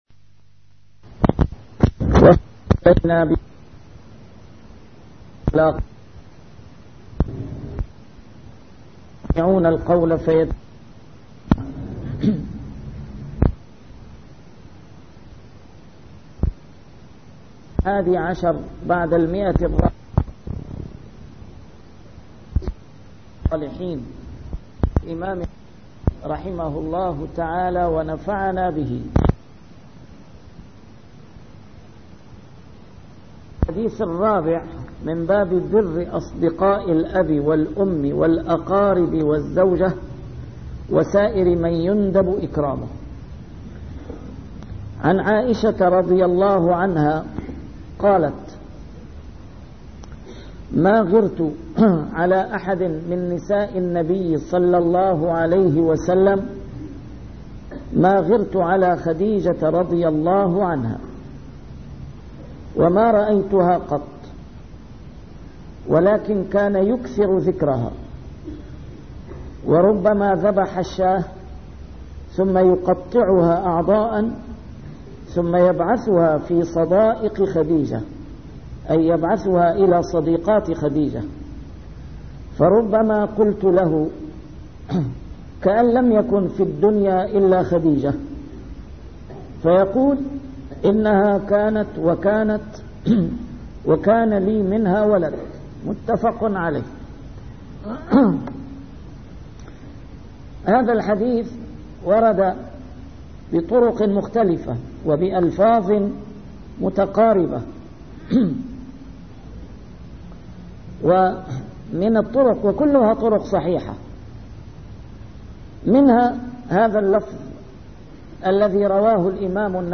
A MARTYR SCHOLAR: IMAM MUHAMMAD SAEED RAMADAN AL-BOUTI - الدروس العلمية - شرح كتاب رياض الصالحين - 410- شرح رياض الصالحين: بر أصدقاء الأب والأم